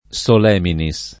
[ S ol $ mini S ]